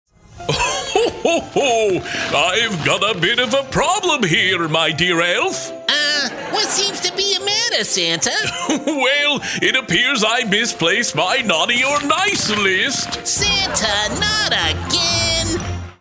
Male
Adult (30-50), Older Sound (50+)
Santa And Elf For Memory Loss.
0727Christmas_Santa.mp3